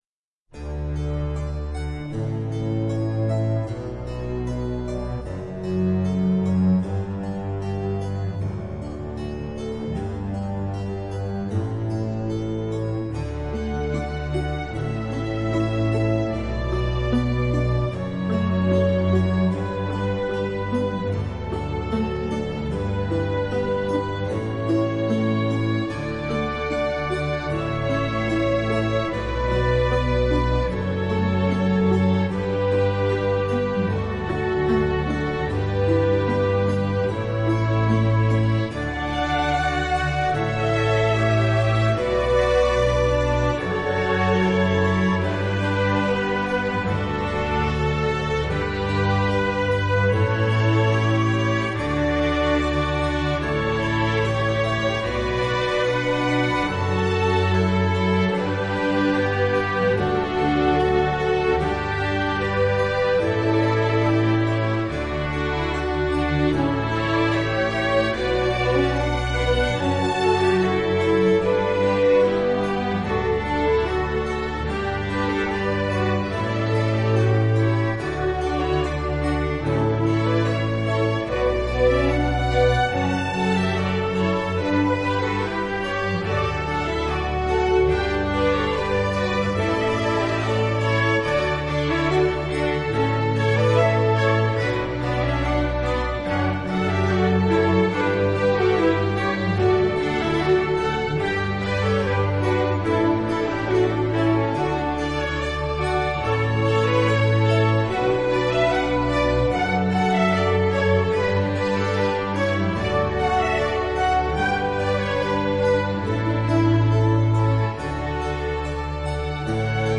Orquestra de corda
United States Air Force Band. Canon (2004) - Strolling Strings (CC BY-SA)
Xeralmente é unha agrupación ideal para tocar en espazos de tamaño máis reducido, e adoita contar con entre oito e doce músicos. A pesar das súas pequenas dimensións, mantén o espírito das súas irmáns maiores pero soamente alberga instrumentos da familia de corda (violín, viola, violonchelo e contrabaixo).
A súa sonoridade é máis íntima e delicada.
Orquesta_cuerda_(1).mp3